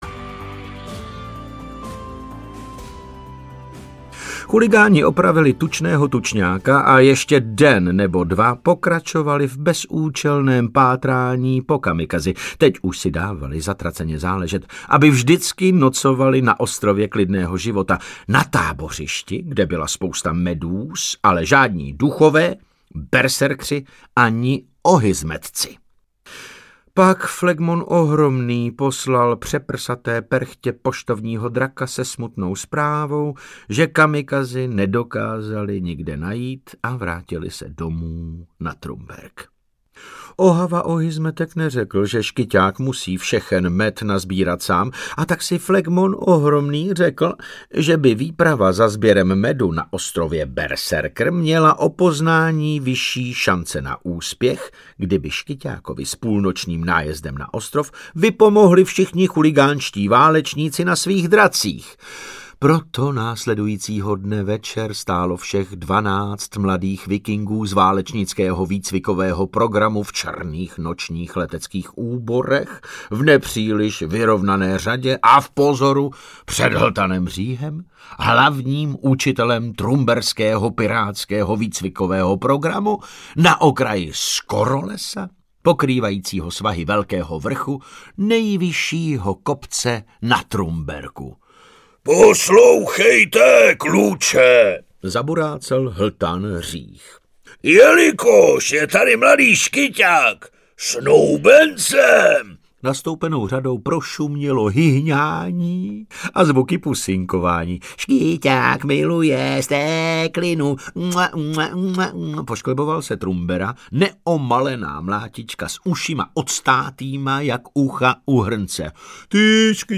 Jak zlomit dračí srdce audiokniha
Ukázka z knihy
• InterpretDavid Novotný